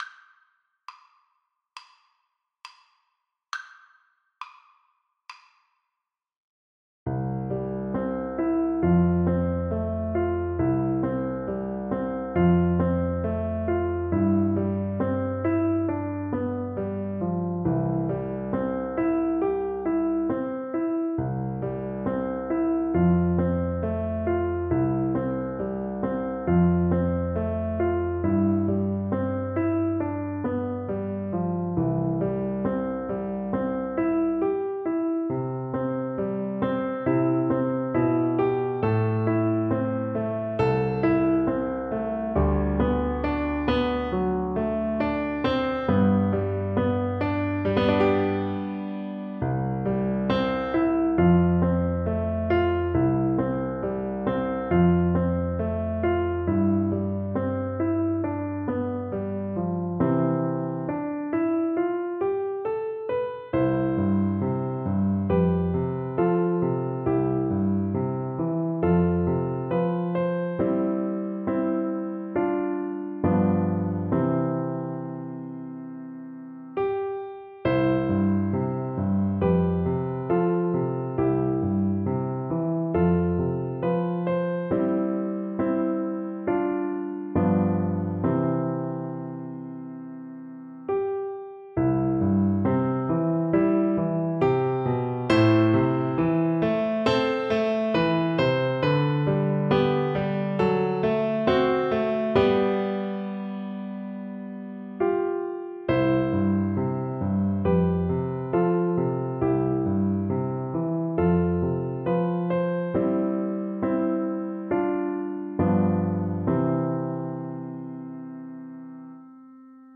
Cello version
Andante
4/4 (View more 4/4 Music)
Scottish